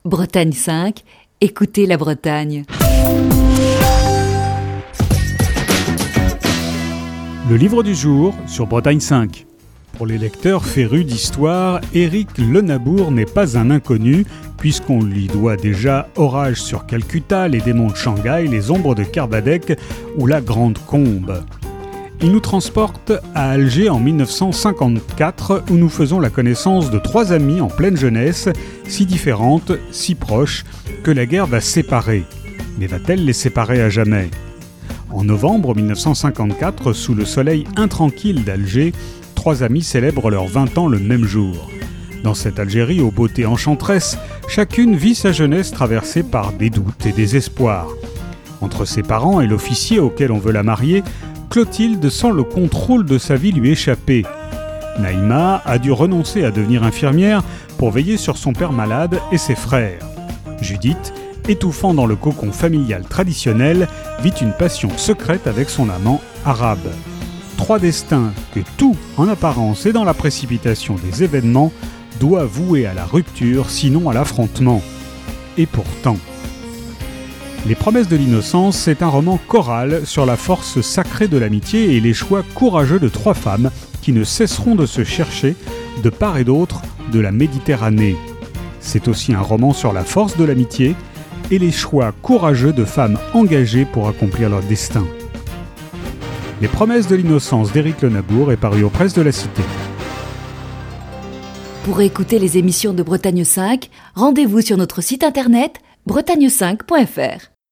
Chronique du 2 juillet 2021.